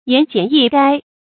言簡意該 注音： ㄧㄢˊ ㄐㄧㄢˇ ㄧˋ ㄍㄞ 讀音讀法： 意思解釋： 見「言簡意賅」。